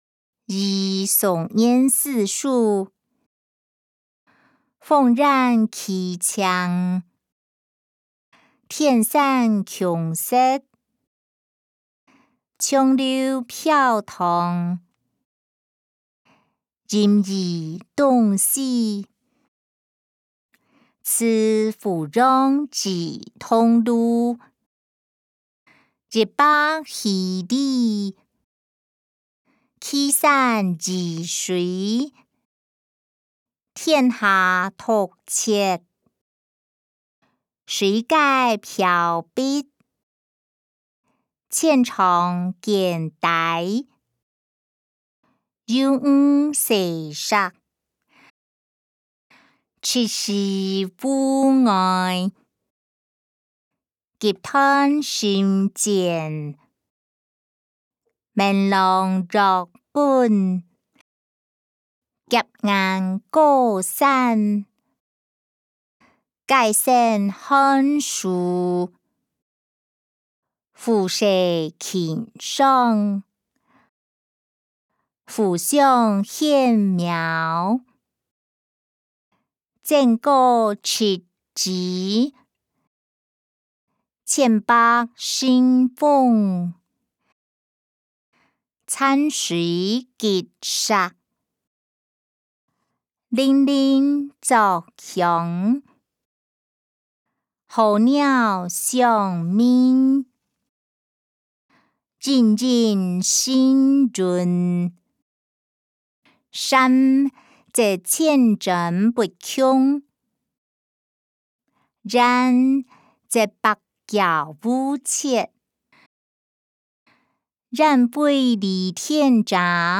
歷代散文-與宋元思書音檔(海陸腔)